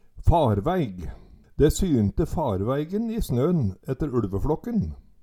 farvæig - Numedalsmål (en-US)